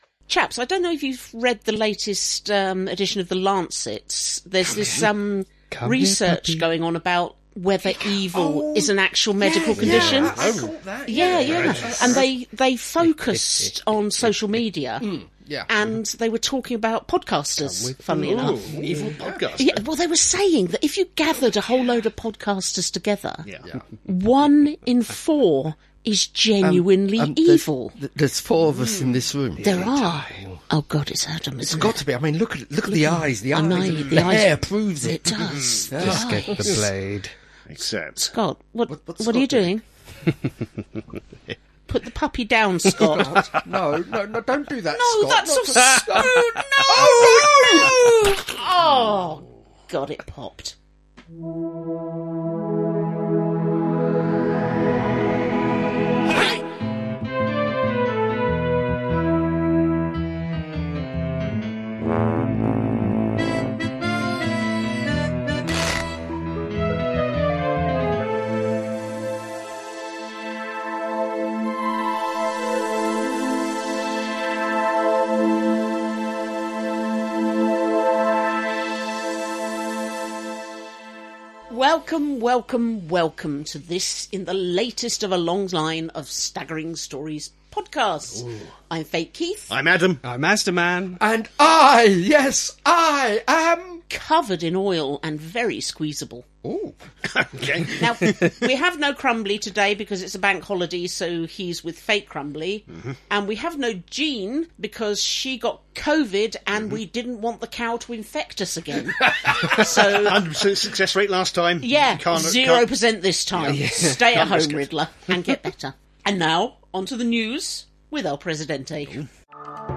00:00 – Intro and theme tune.
83:33 — End theme, disclaimer, copyright, etc.